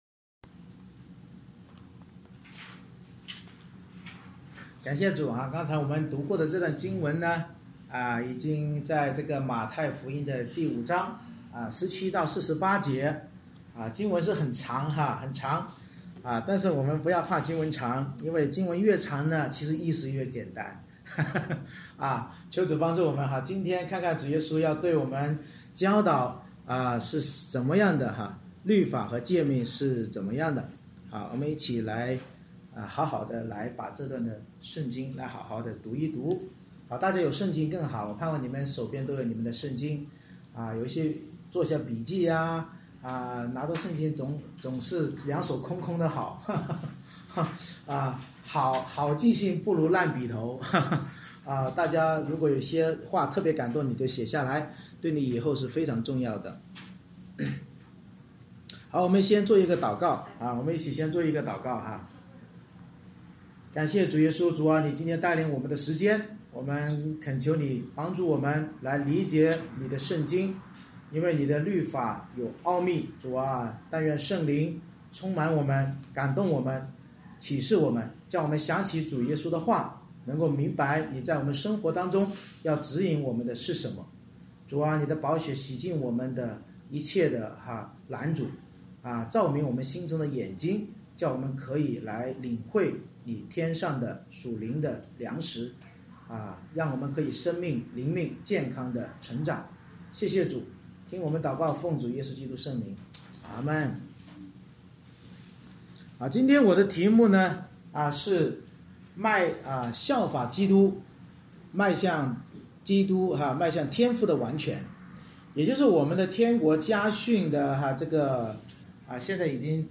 马太福音5:17-48 Service Type: 主日崇拜 君王耶稣的山上家训纠正了文士和法利赛人对诫命的歪曲解释，教导我们要效法基督成全并遵行圣经，像天父那样爱人如己才能进入天国。